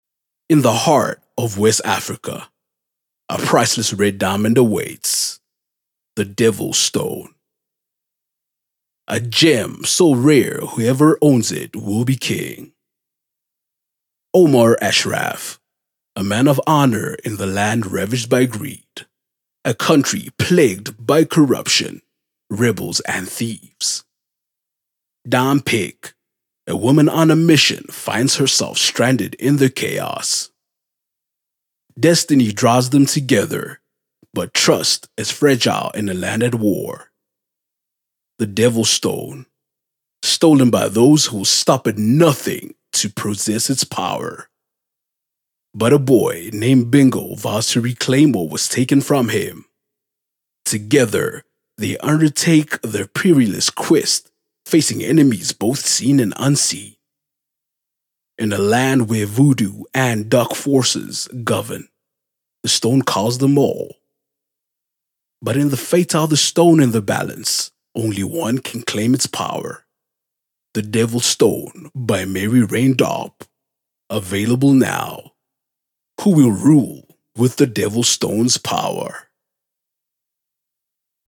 confident, energetic
My deep, captivating voice has become my signature, allowing me to engage audiences across various platforms, from commercials to online campaigns.
My demo reels